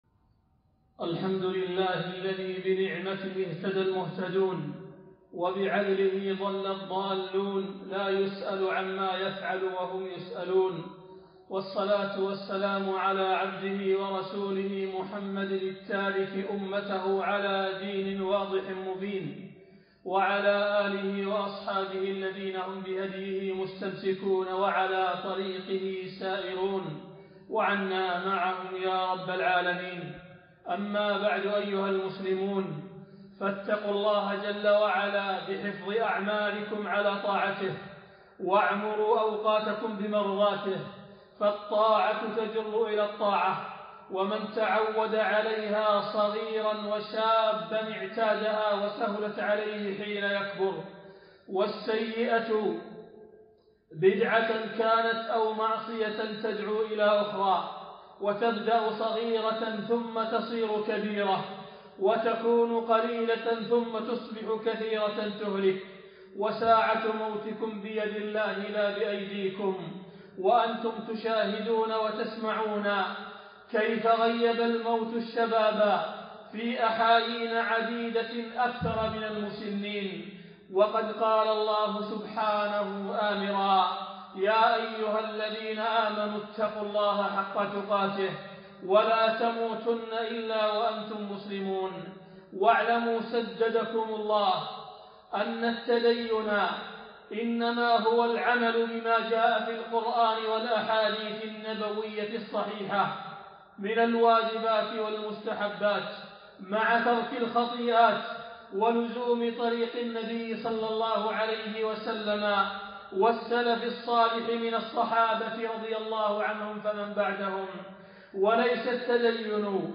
خطبة - لماذا يُحذِّر أهل السُّنة من جماعة الدعوة والتبليغ